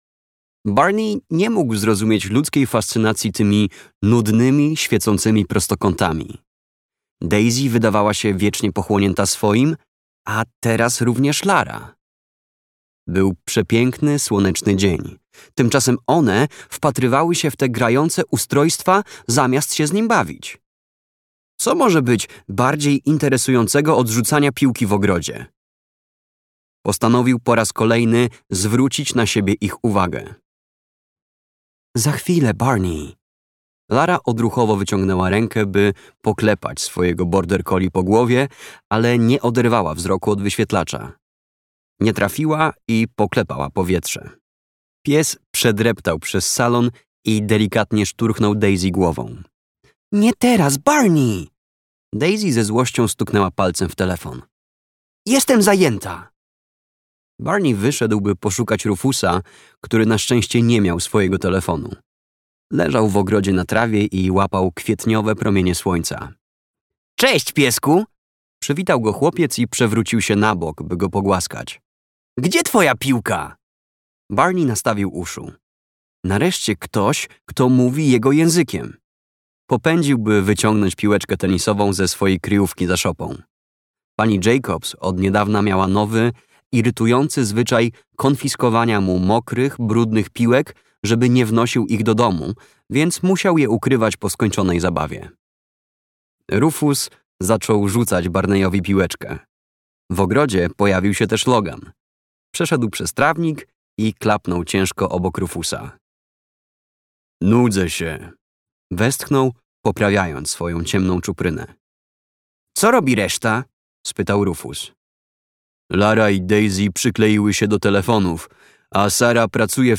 Pogoń przez kontynent. Przygodowcy, tom 4 - Jemma Hatt - audiobook